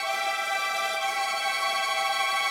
GS_Viols_95-E2.wav